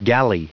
Prononciation du mot galley en anglais (fichier audio)
Prononciation du mot : galley